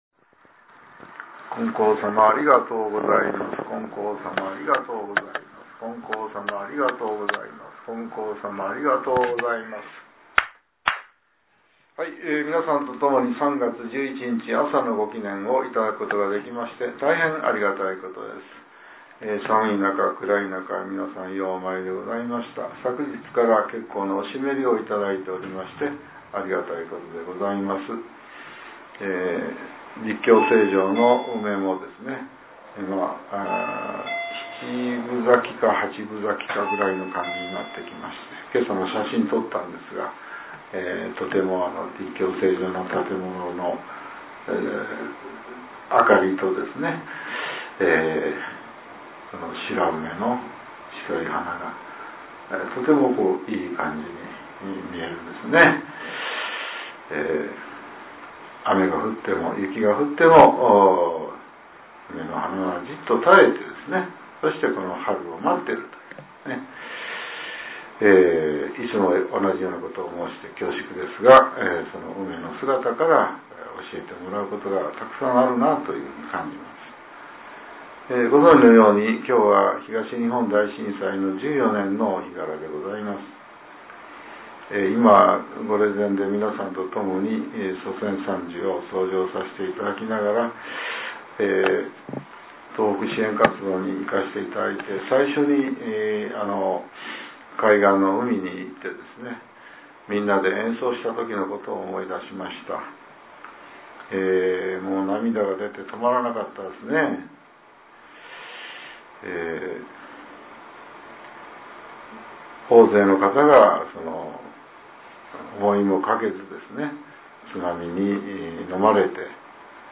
令和７年３月１１日（朝）のお話が、音声ブログとして更新されています。